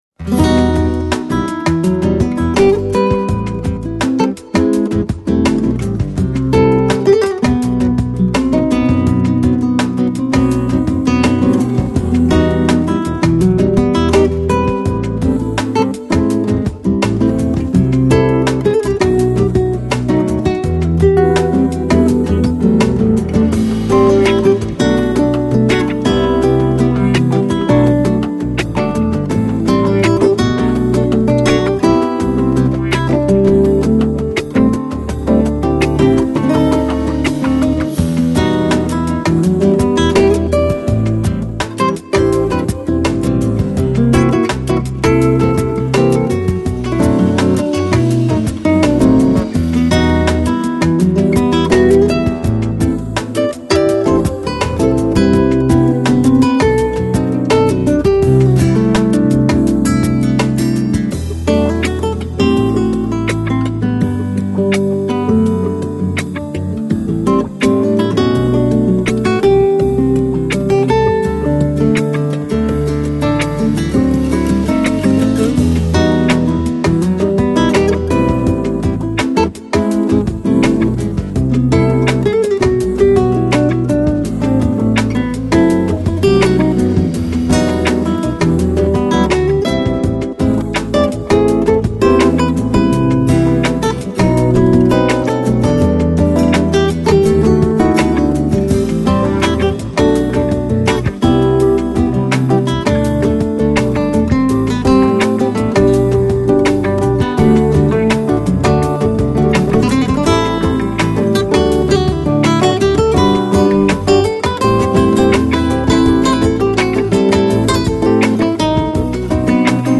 Electronic, Lounge, Chill Out, Downtempo, Flamenco